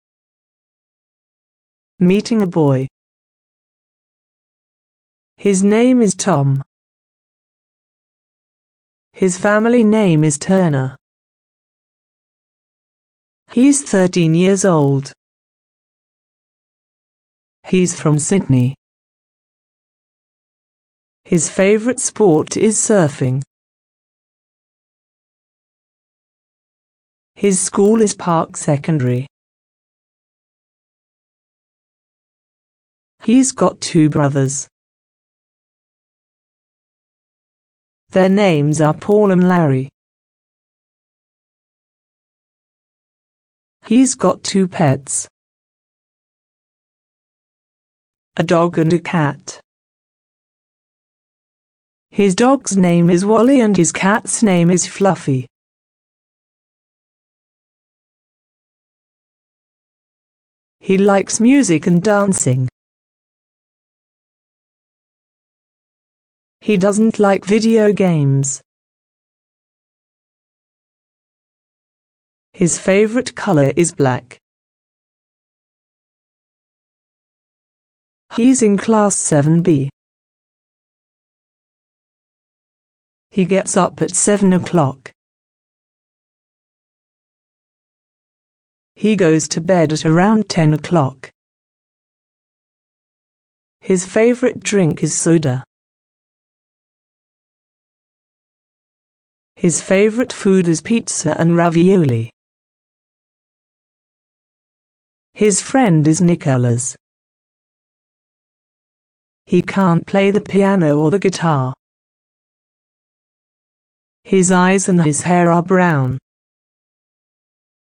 Listen to a presentation of a boy